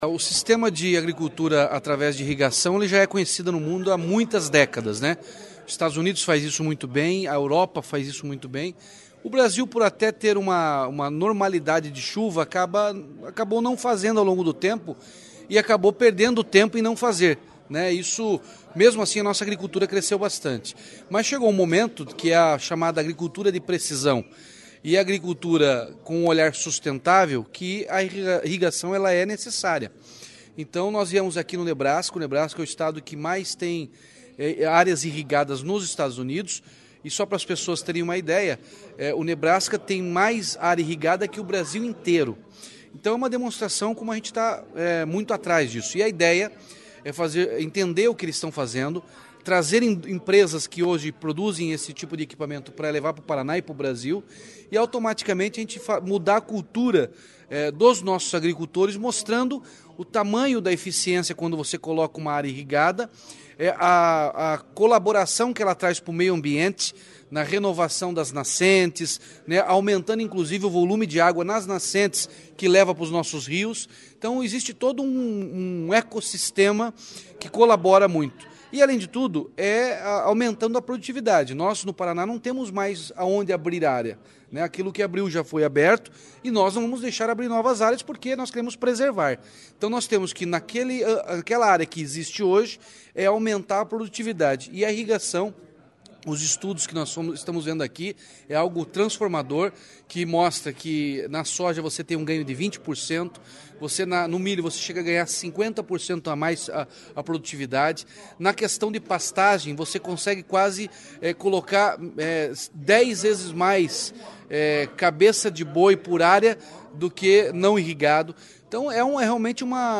Sonora do governador Ratinho Junior sobre a reunião com a Lindsay Corporation, abrindo a missão internacional ao Nebraska